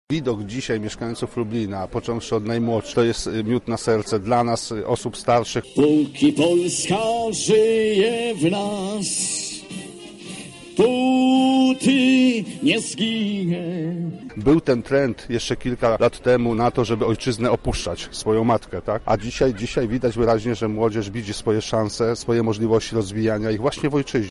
Lublinianie jak co roku na placu Zamkowym uczcili odzyskanie niepodległości przez Polskę.